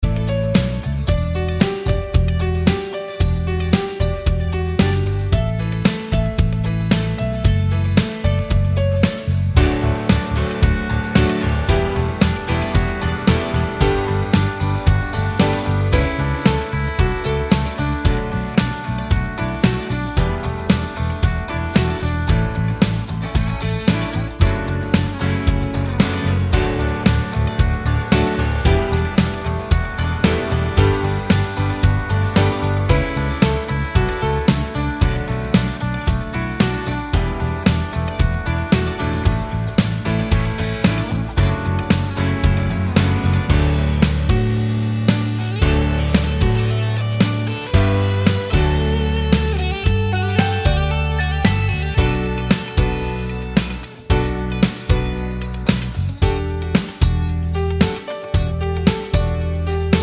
Duet Version Pop (1980s) 4:19 Buy £1.50